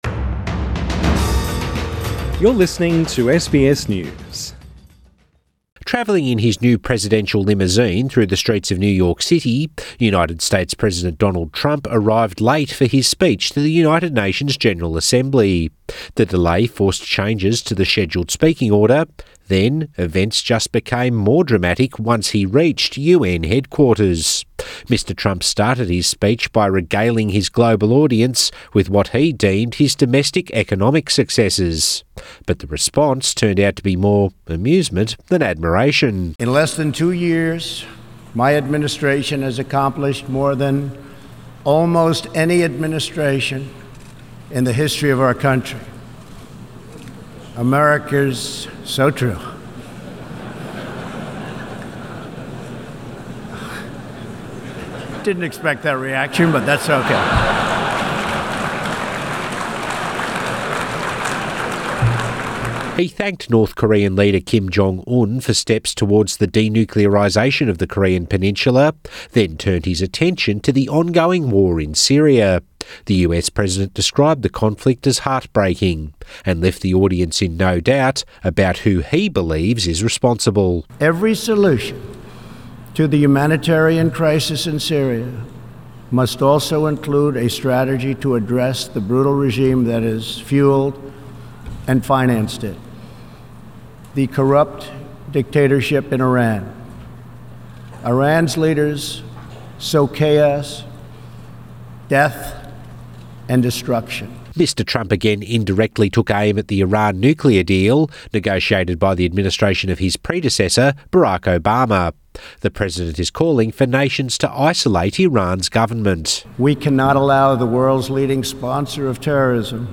Trump UN speech triggers laughter, then criticism
Addressing the United Nations General Assembly in New York, his boasts on domestic policy in front of an audience from more than 190 countries triggered the laughter.
US president Donald Trump addresses the UN Source: AAP